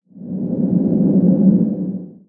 cave6.ogg